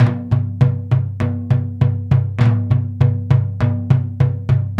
Index of /90_sSampleCDs/Spectrasonics - Supreme Beats - World Dance/BTS_Tabla_Frames/BTS_Frame Drums